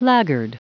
Prononciation du mot laggard en anglais (fichier audio)
Prononciation du mot : laggard